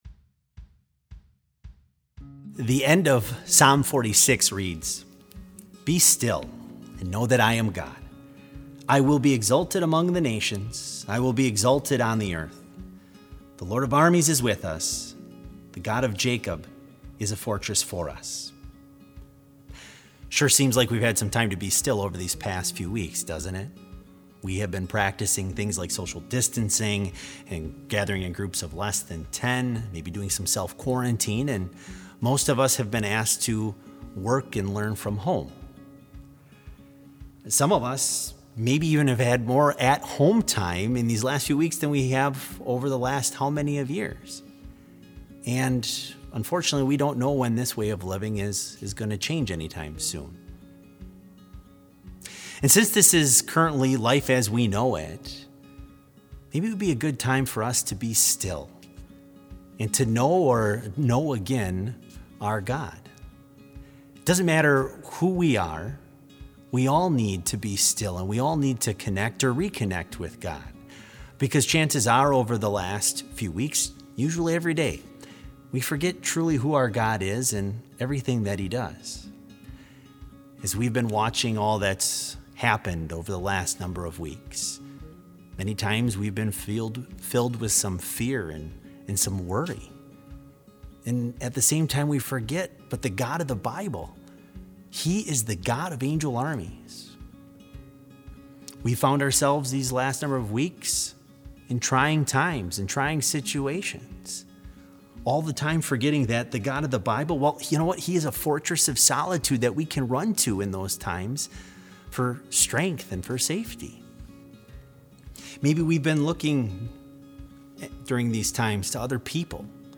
Complete service audio for BLC Devotion - May 5, 2020